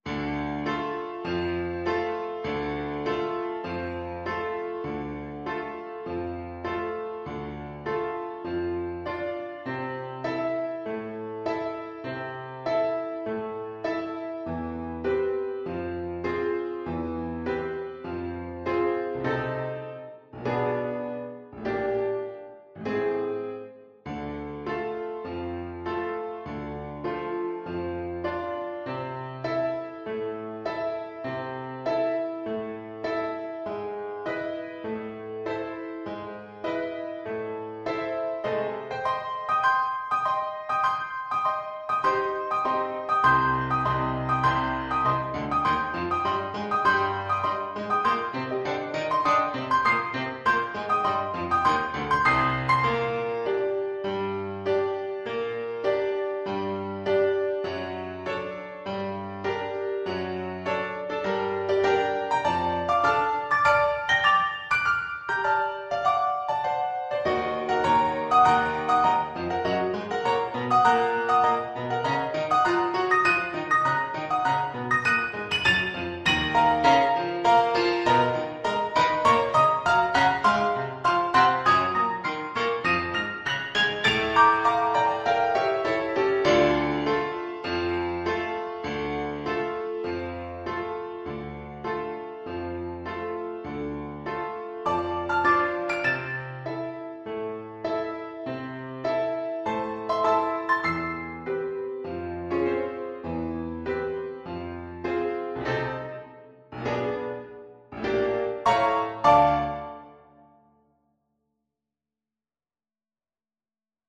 Classical (View more Classical Trumpet Music)